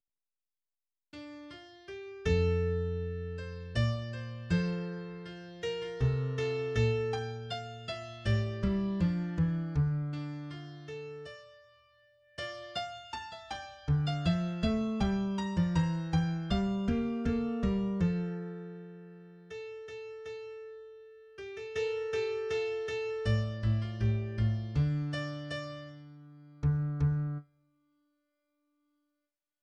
3rd verse